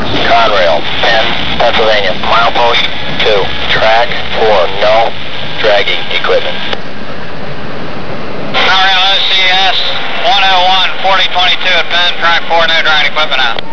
Radio Alarm Detector